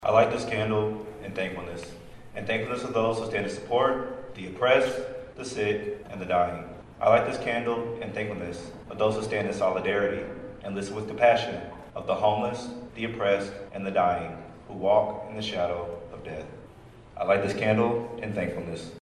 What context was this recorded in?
Kansas State University honored the life and legacy Dr. Martin Luther King Jr. with their annual candle lighting and wreath laying ceremony on campus Friday.